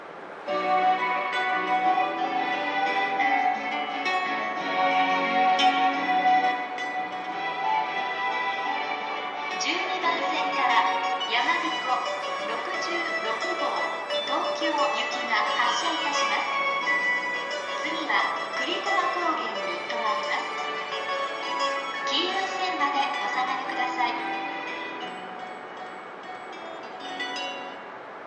○発車メロディー○
基本的にフルコーラスが流れています。通過音と被りやすいのが難点ですね。